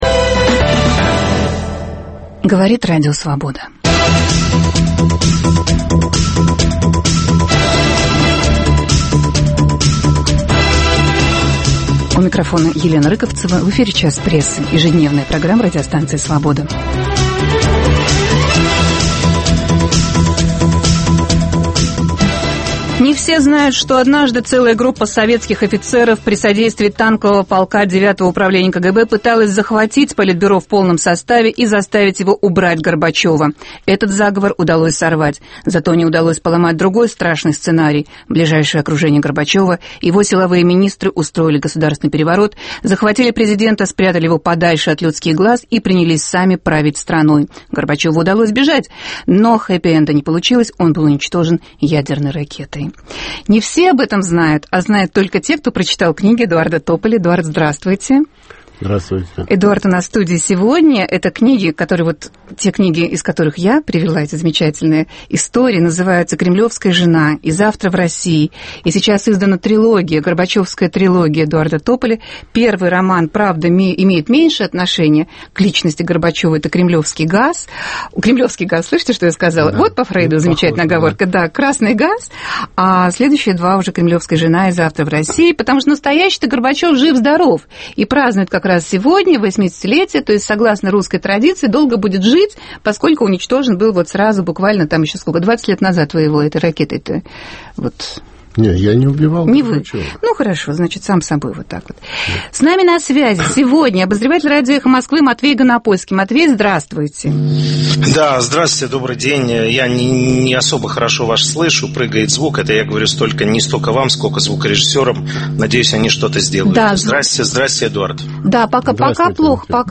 Разговор о президенте СССР в день его 80-летнего юбилея. В студии - писатель, автор "Горбачевской трилогии" Эдуард Тополь. На связи из Тбилиси - журналист Матвей Ганапольский.